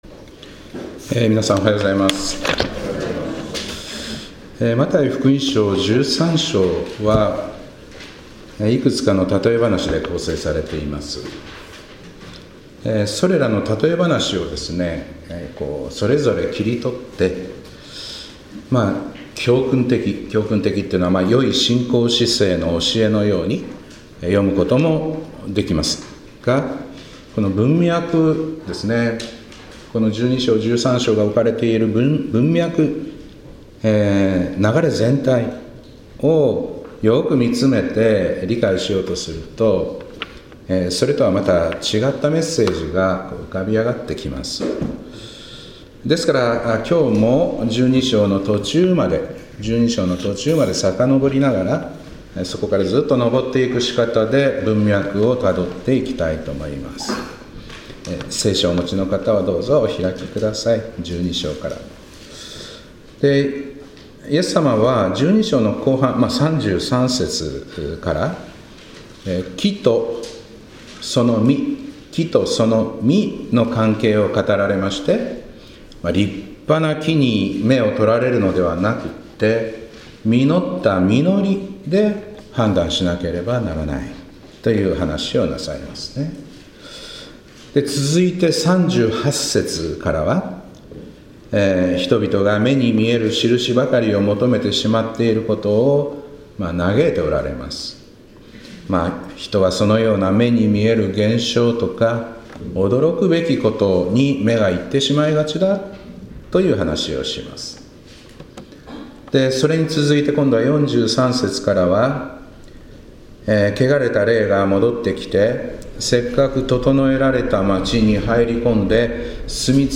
2025年2月16日礼拝「真珠のようなあなた」